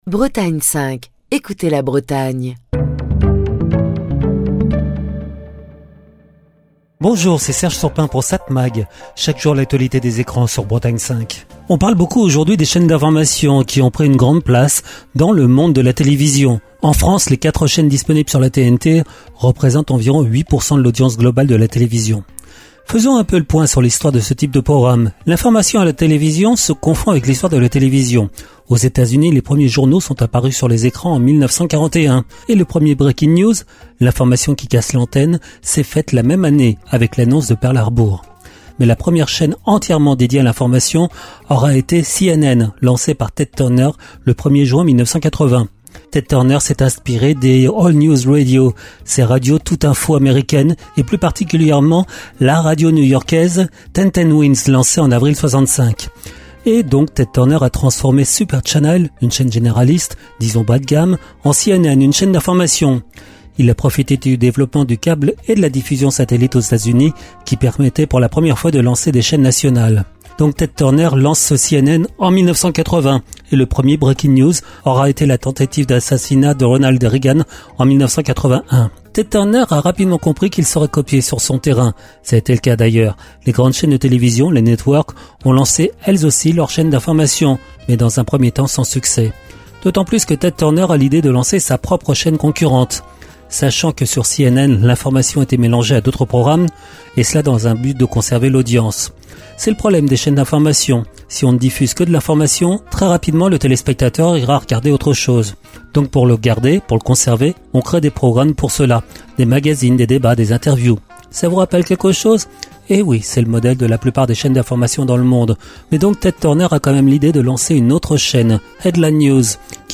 Chronique du 11 mars 2025.